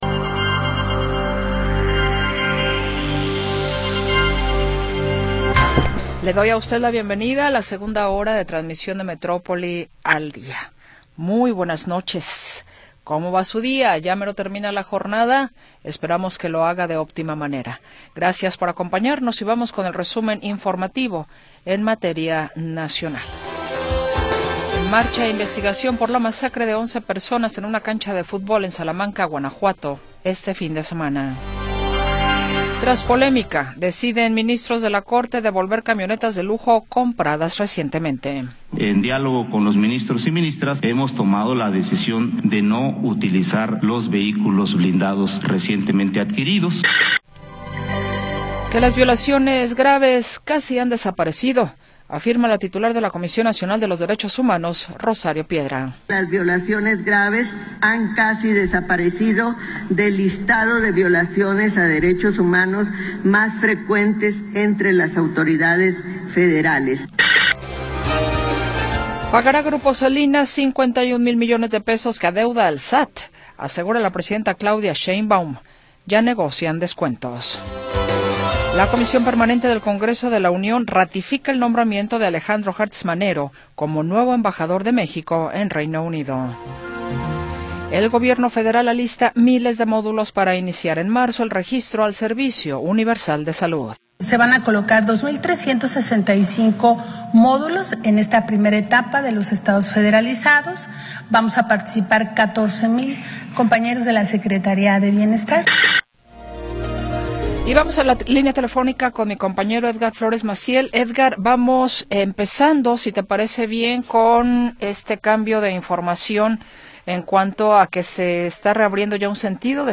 Segunda hora del programa transmitido el 26 de Enero de 2026.